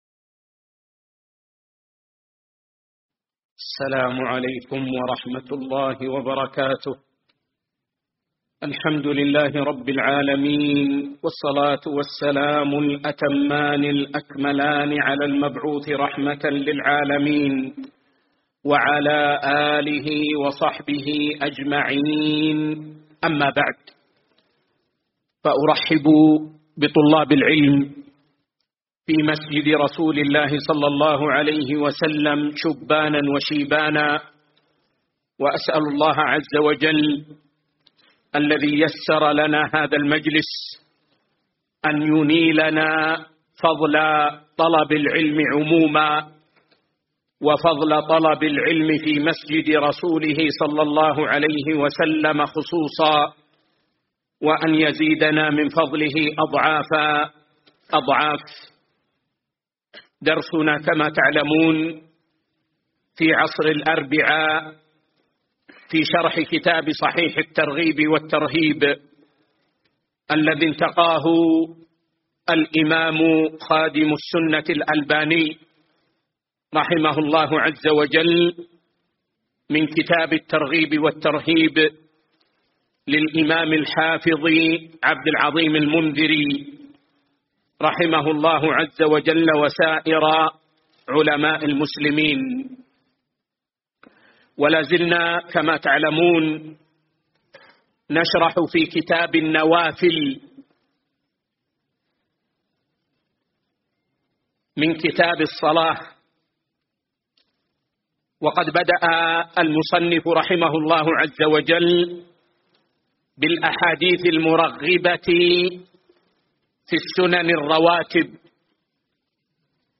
الدرس 107